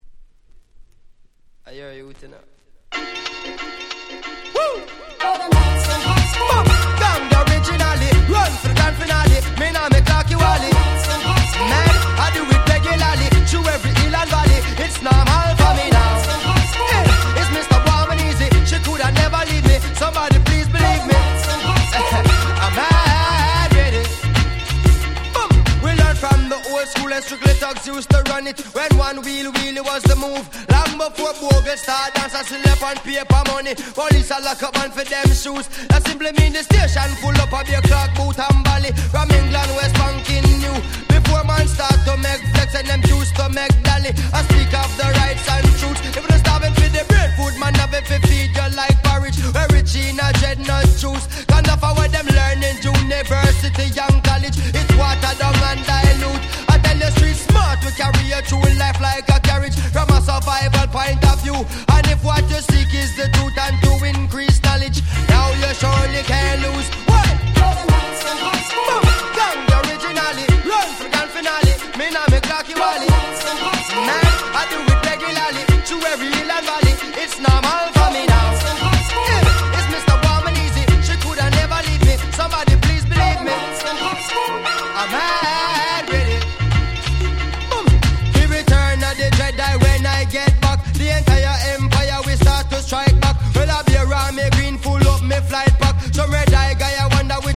05' Super Hit Reggae !!